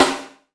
SNARE 001.wav